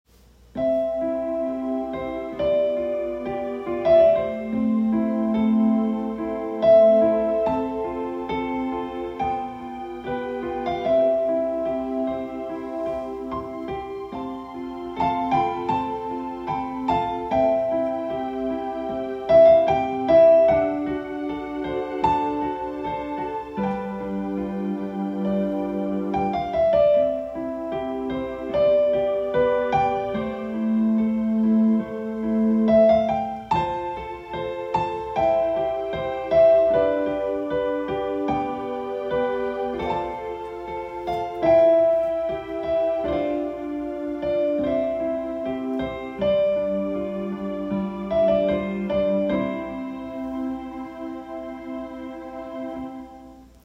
INCIDENTAL MUSIC FOR CEREMONY